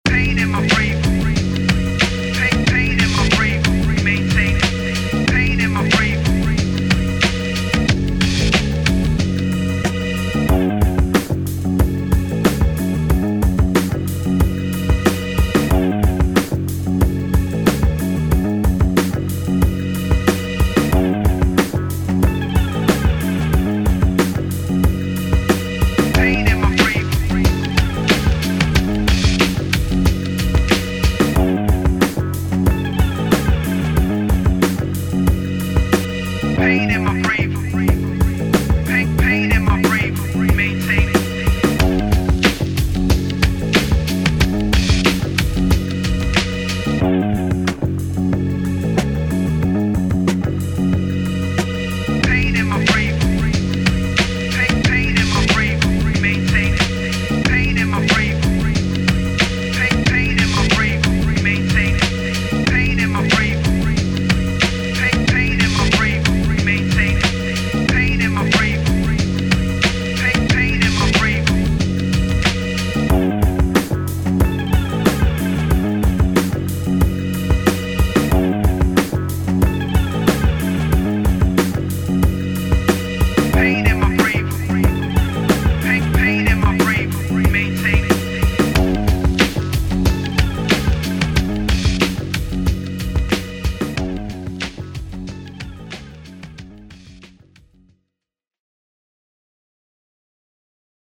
free beat tape